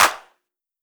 Claps
JJClap (17).wav